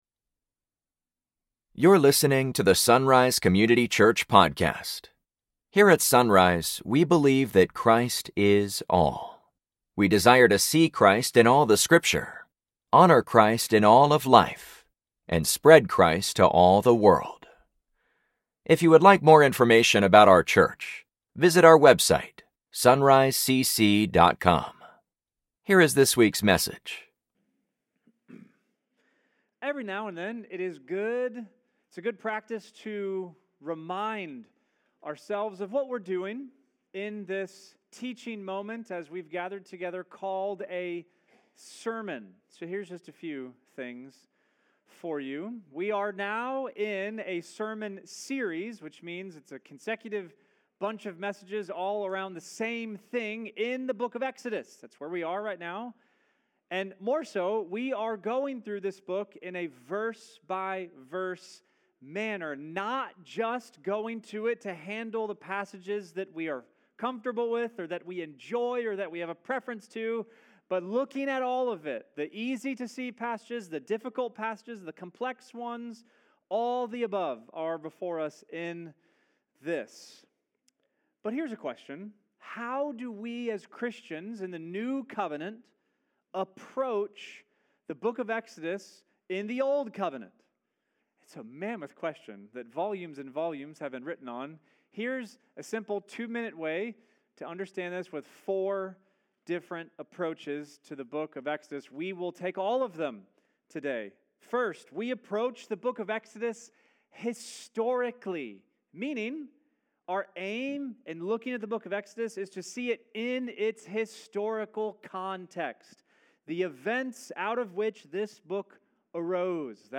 Sunday Mornings | SonRise Community Church
Every now and then it’s good to remind ourselves what we’re doing here in this teaching moment called a sermon.